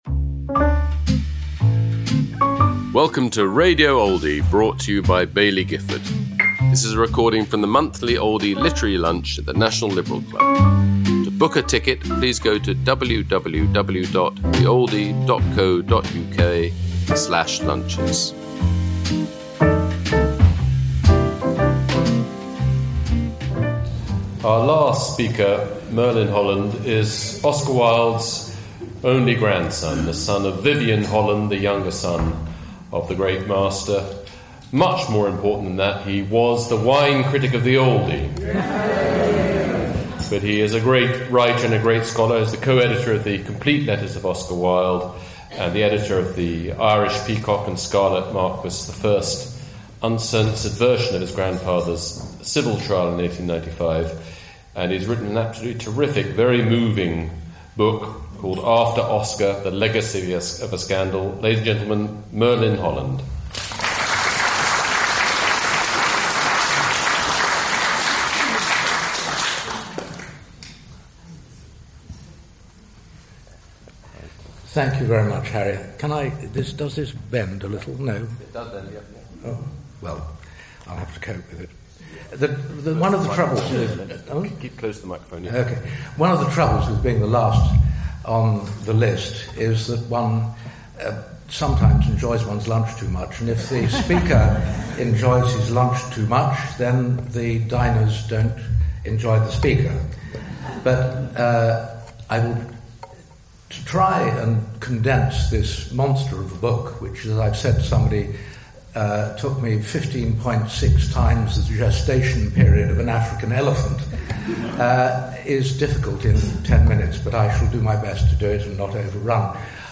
Merlin Holland speaking about his new book, After Oscar: The Legacy of a Scandal, at the Oldie Literary Lunch, held at London’s National Liberal Club, on November 4th 2025.